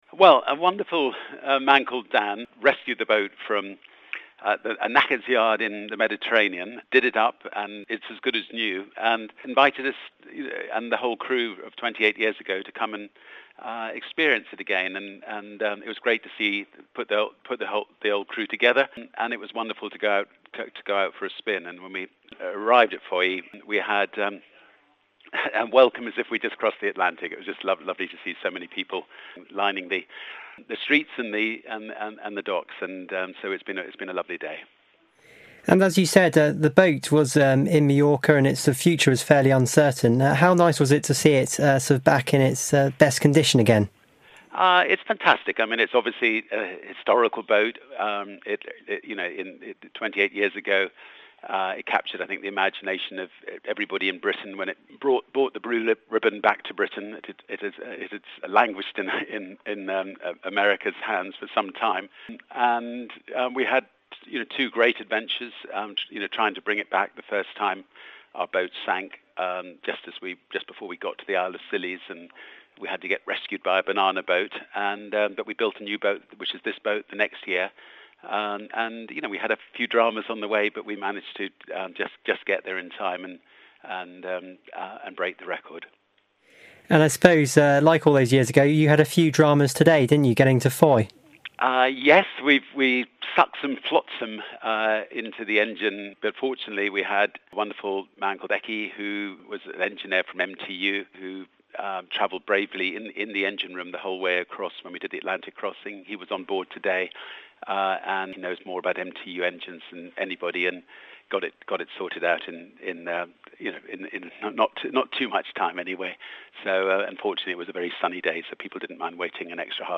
by phone